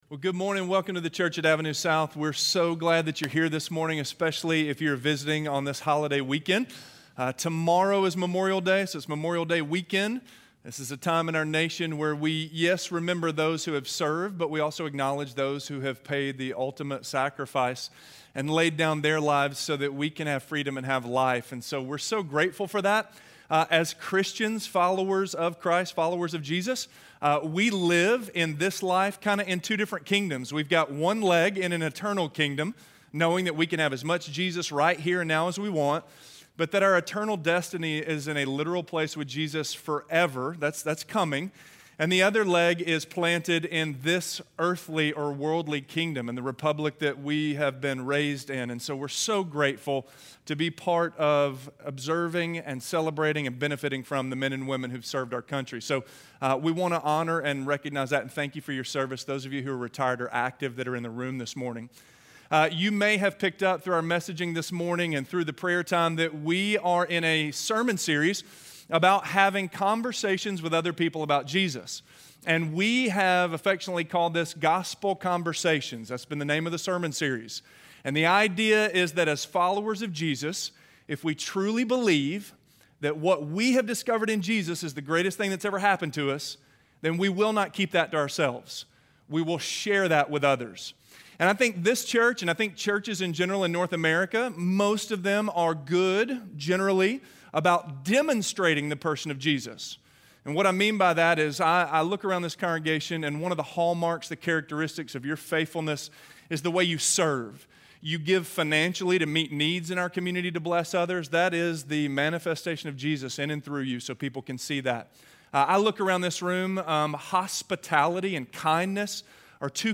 That They Might Seek God - Sermon - Avenue South